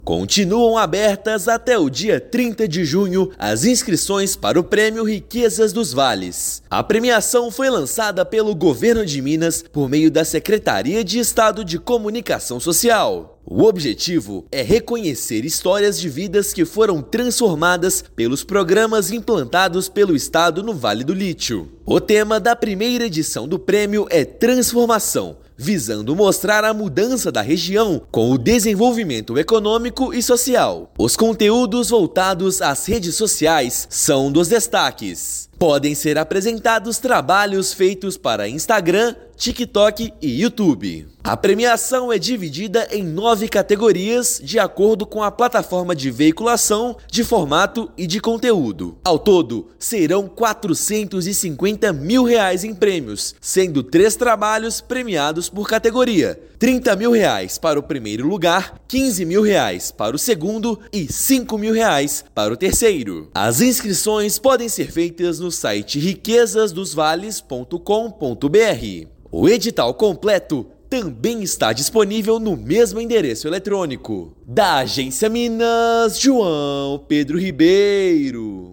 [RÁDIO] Riquezas dos Vales premia três categorias voltadas para as redes sociais
Serão avaliados vídeos produzidos para Instagram, TikTok e Youtube, que promovam a cultura e a identidade da região do Vale do Lítio. Ouça matéria de rádio.